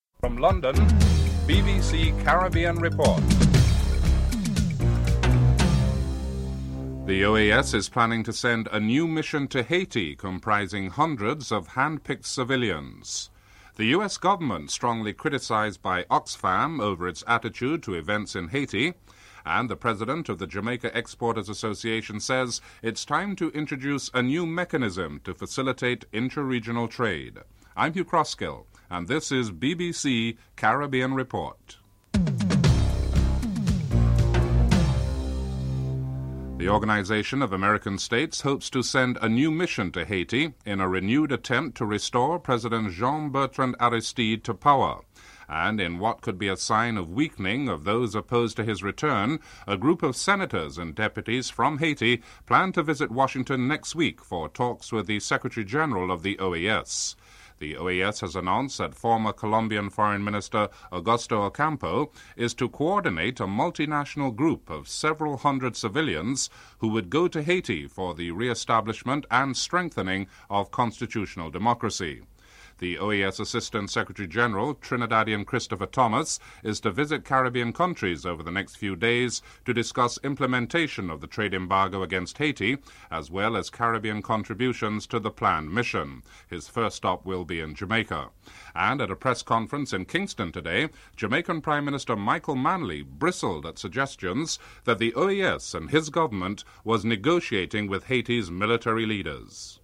1. Headlines (00:00-00:35)
Comments from Jamaica’s Prime Minister, Michael Manley (00:36-02:50)